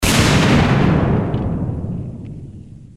BOOM.ogg